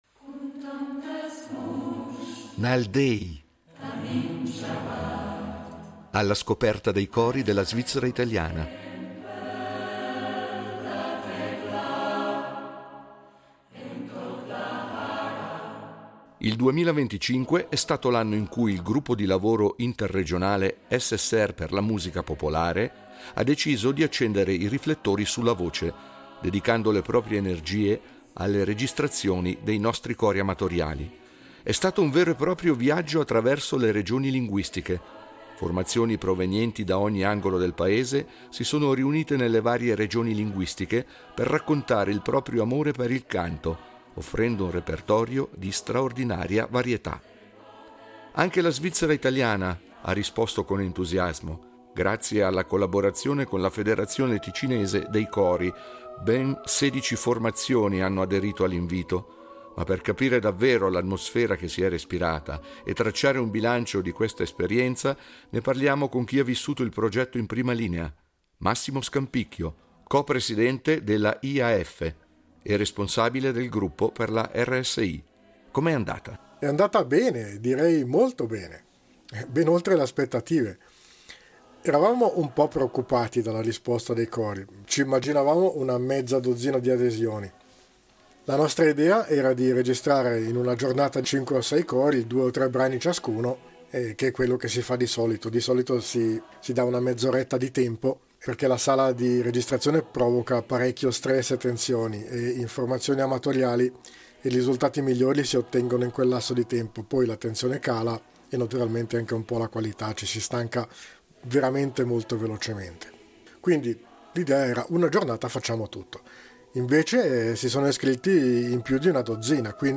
Nal déi, cori della svizzera italiana
Nel 2025, l’IAF della SSR ha registrato cori amatoriali in tutta la Svizzera, culminando con le registrazioni dei cori della Svizzera italiana a Faido per il progetto “déi”.